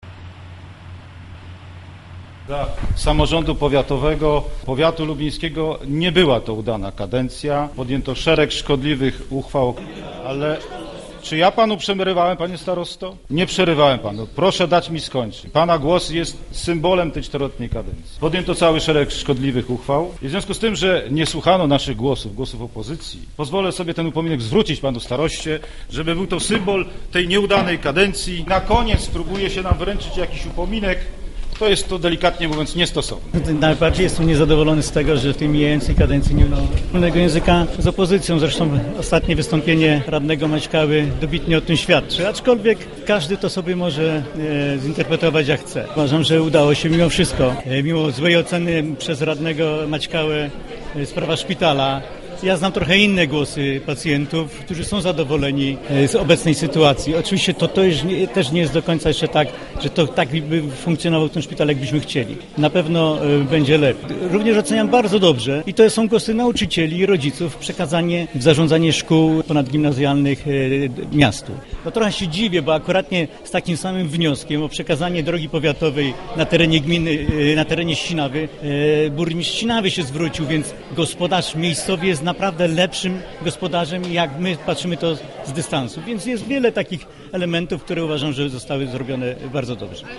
Ciekawsza była za to wymiana zdań radnego Tadeusza Maćkały z szefem powiatu Adamem Myrdą.
sesjapowiat.mp3